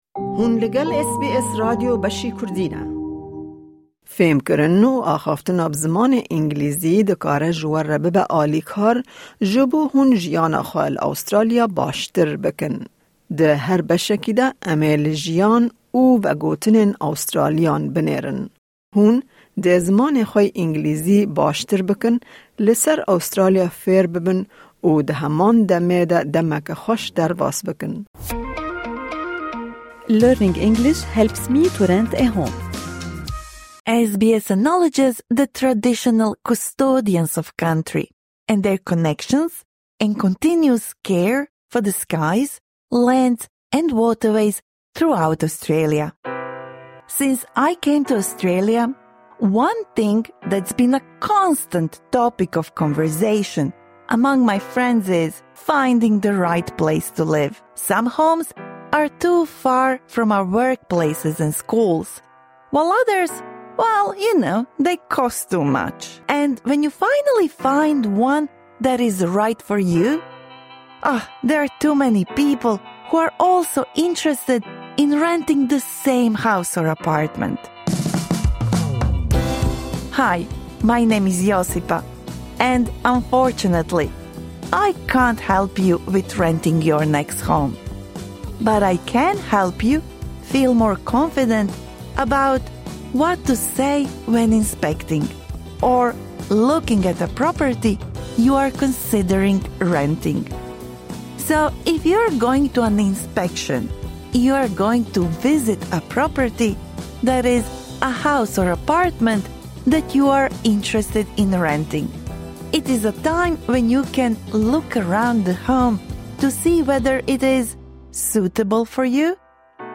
Ev ders ji şagirtên navîn re baş e. Piştî guhdarîkirinê, zanîna xwe bi kiwîza me biceribîne.